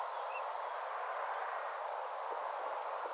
onko_tuo_mekaaninen_jonkin_laitteen_aani_vaiko_lintu.mp3